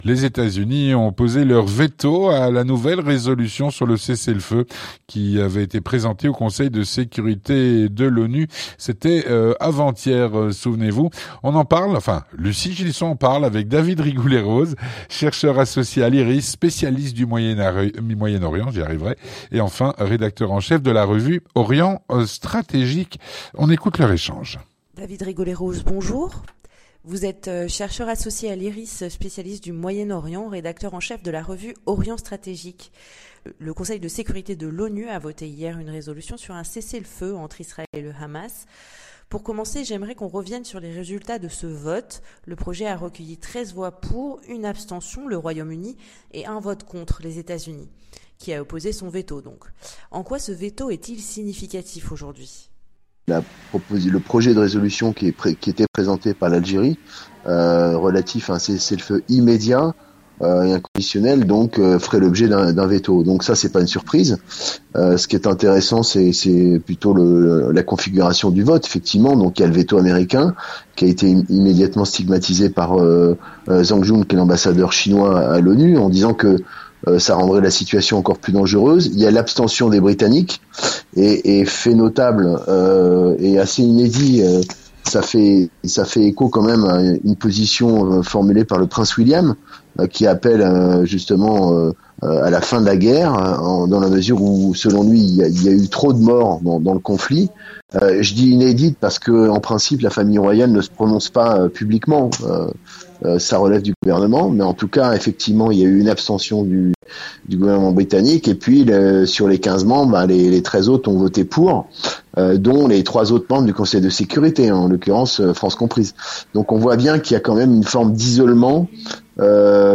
L'entretien du 18H - Véto américain à la nouvelle résolution de cessez-le-feu, voté au Conseil de sécurité de l’ONU, hier.